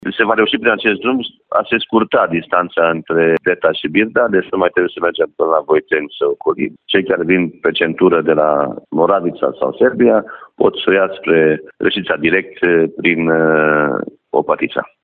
Lucrările vor începe în acest an, iar finanţarea vine de la Consiliul Judeţean Timiş. Este vorba despre cinci kilometri de drum pe raza oraşului şi încă cinci pe raza comunei Birda, spune primarul din Deta, Petru Roman.